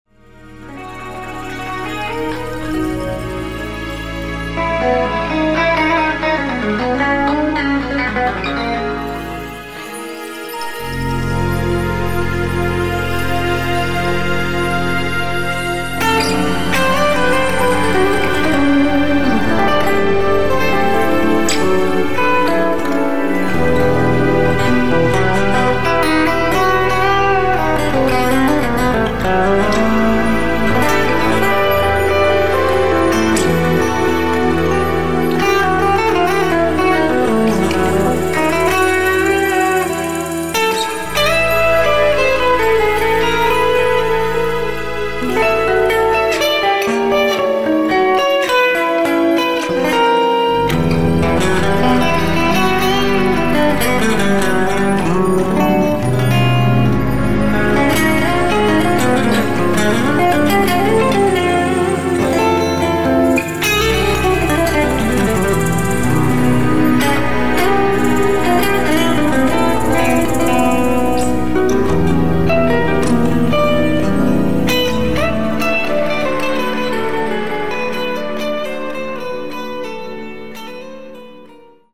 Pure instrumental timeless guitar music...
Al l tracks composed, played, arranged and mixed by yours.